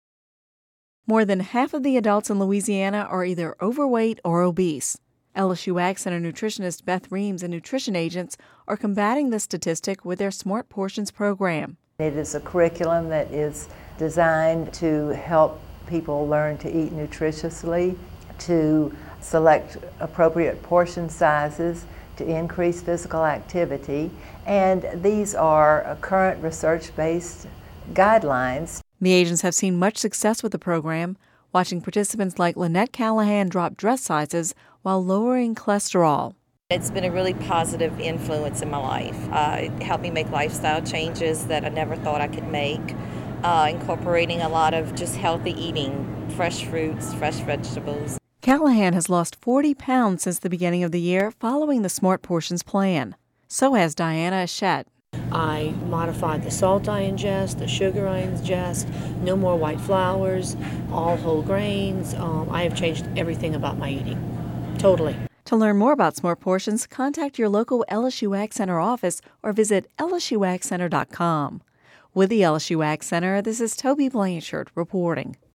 (Radio News 12/13/10) More than half of the adults in Louisiana are either overweight or obese.